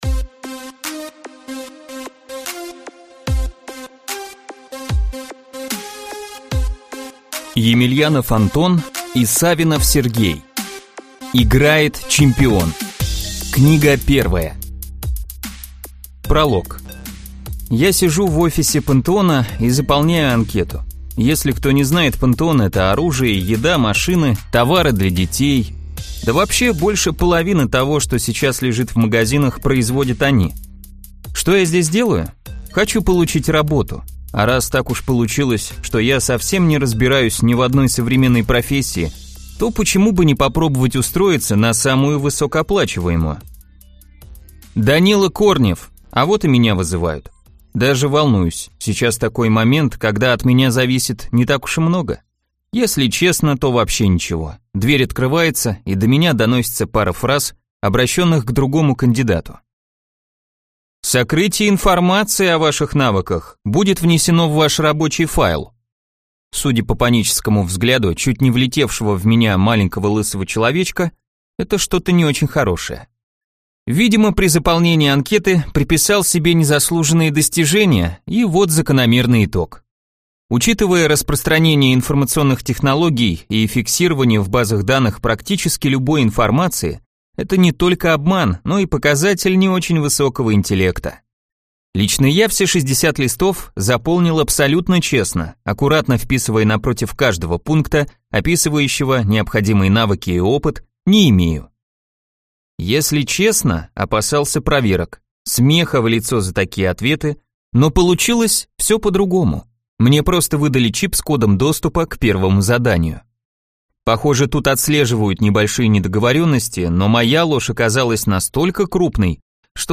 Аудиокнига Играет чемпион. Настоящий герой из жанра ЛитРПГ - Скачать книгу, слушать онлайн